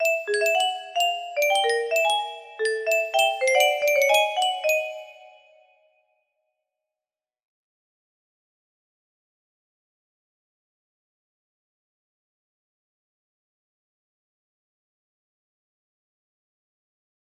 백운고 music box melody